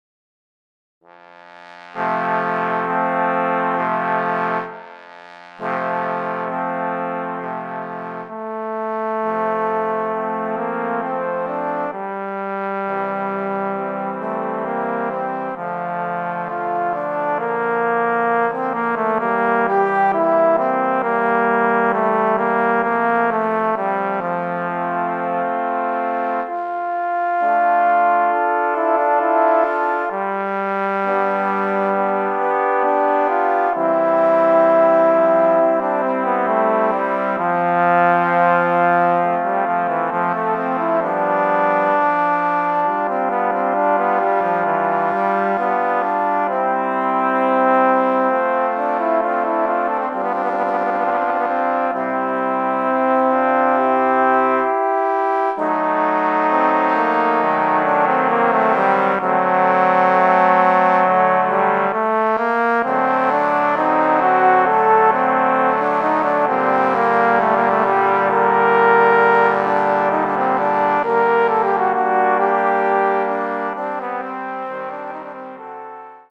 Voicing: Trombone Ensemble